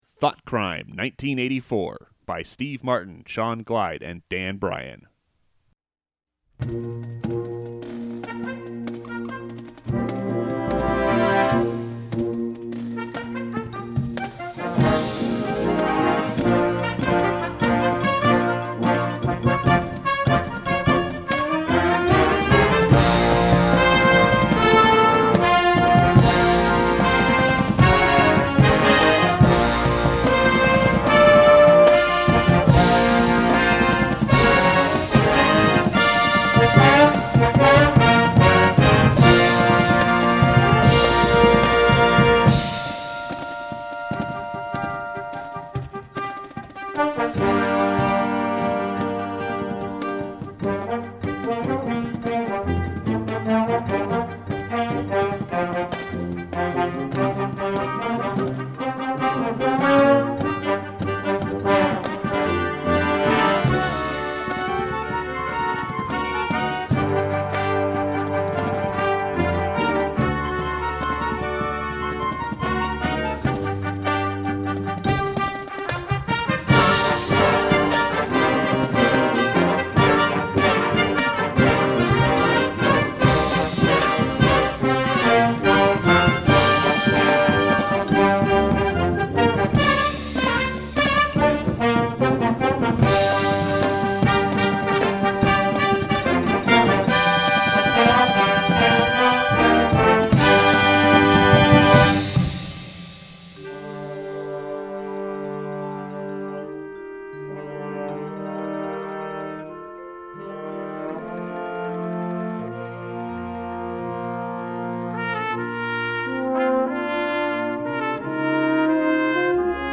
thrilling Marching Band Show scored for smaller bands